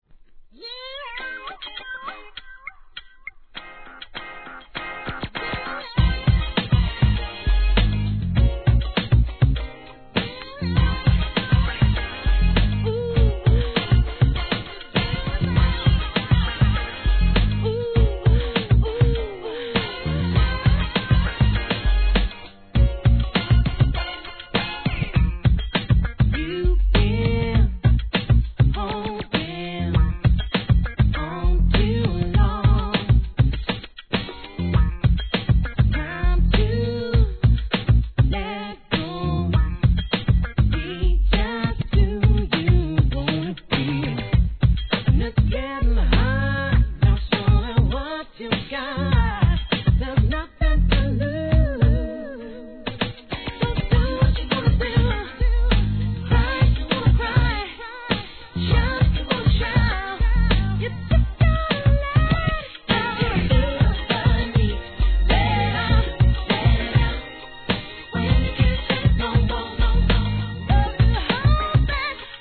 HIP HOP/R&B
UKアーバンSOUL